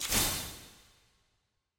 sfx-eog-ui-silver-burst.ogg